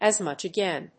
アクセントas mùch agáin (as…)